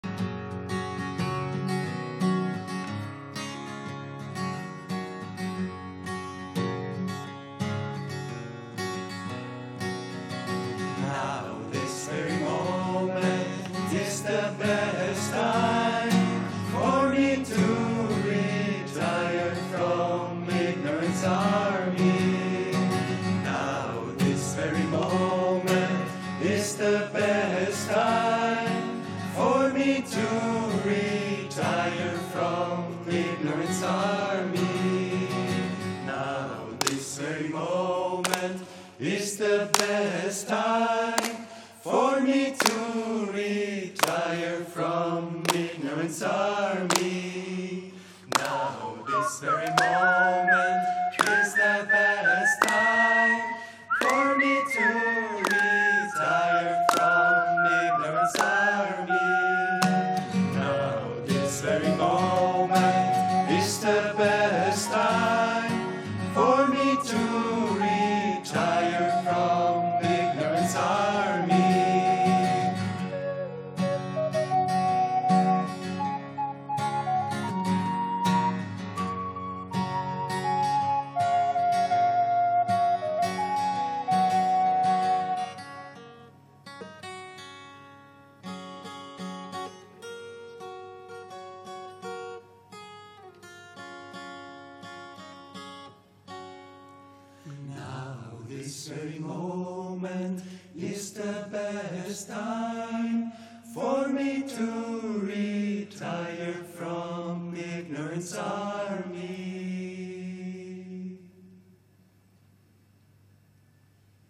Meditation music by Sri Chinmoy’s students, Jan-Feb 2016
On a recent spiritual vacation in Sicily, Malta and Malaysia, students of Sri Chinmoy offered many musical performances.
The performances by members of the Sri Chinmoy Centre offer a variety of interpretations, fusing both classical and Eastern traditions, but also seeking to bring out the soulfulness inherent in Sri Chinmoy’s music.